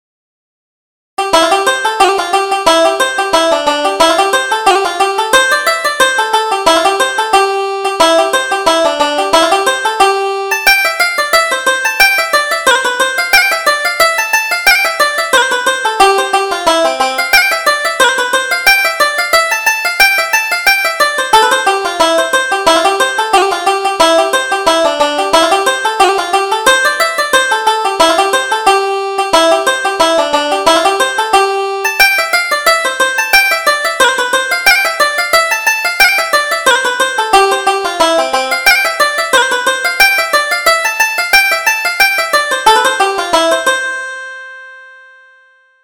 Reel: I Have No Money